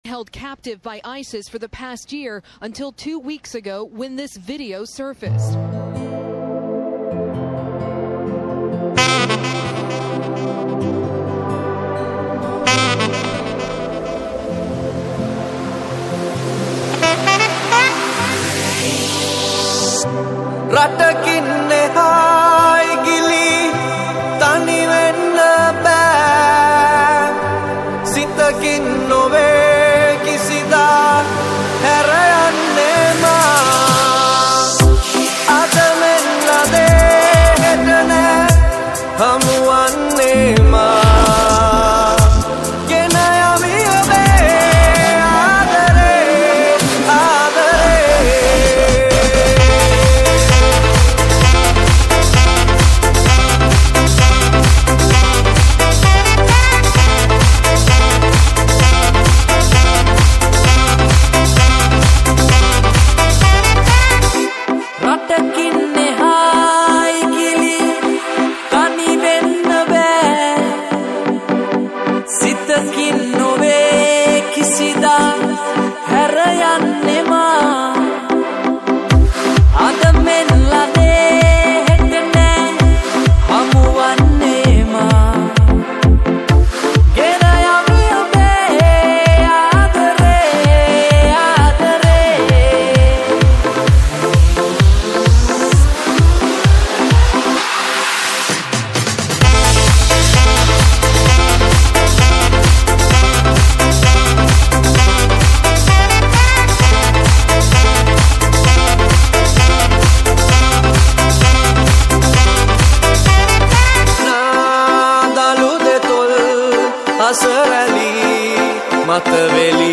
EDM Remake